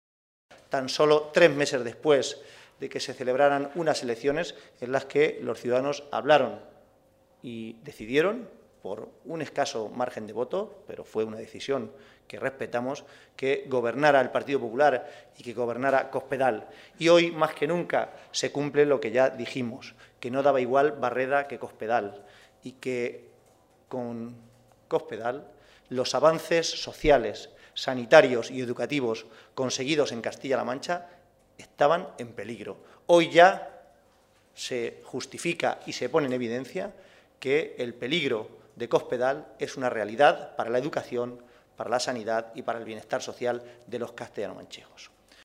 Caballero se pronunciaba así al comparecer ante los medios de comunicación para informar sobre el Comité Regional que los socialistas han celebrado esta tarde en Toledo.